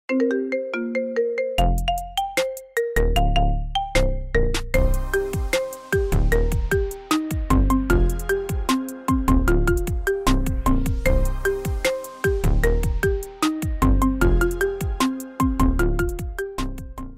Sonnerie Gratuite